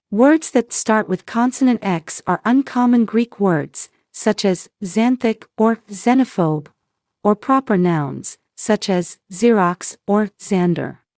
Consonant X has two sounds. Its most common sound is the unvoiced sound it makes at the ends of words or syllables and says: /ks/, /ks/, /ks/, box.
Although rare, when Consonant X is at the beginning of a word it will make a voiced sound: /z/, /z/, /z/, xylophone.
X-xylophone-more-information-AI.mp3